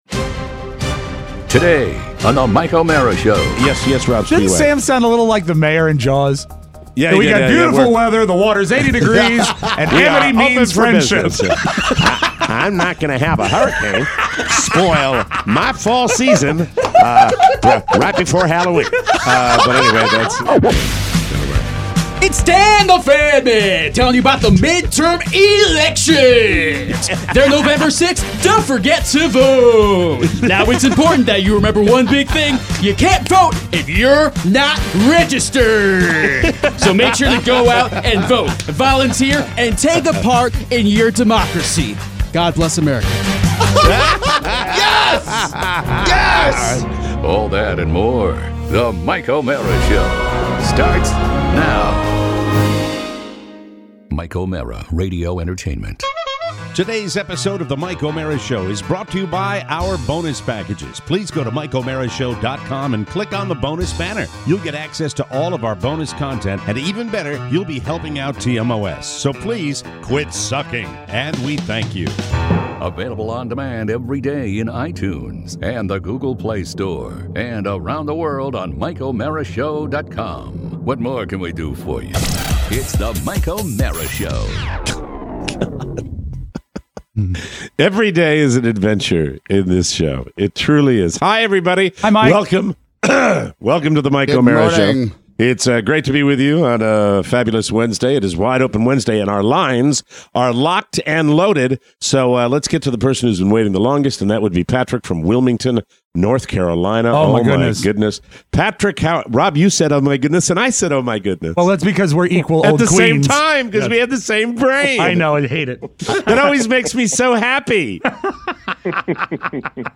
Today… your calls!